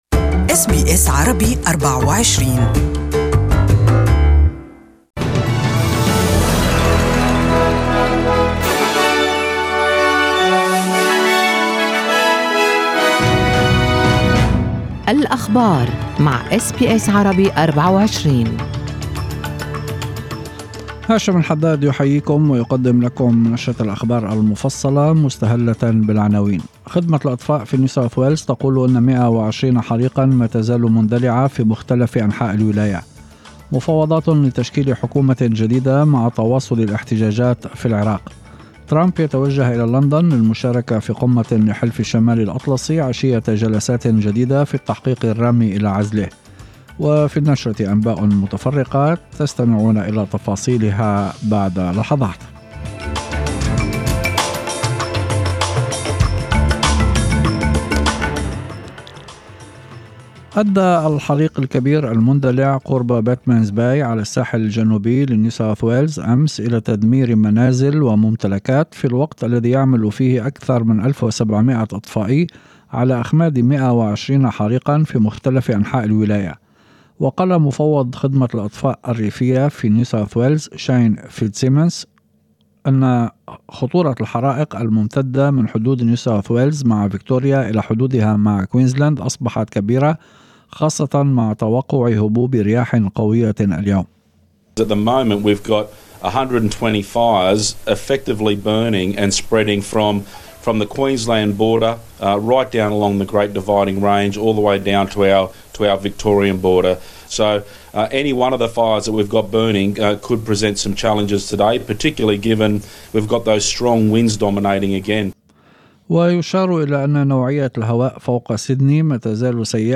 يمكن الاستماع لنشرة الأخبار المفصلة باللغة العربية في التقرير الصوتي أعلاه .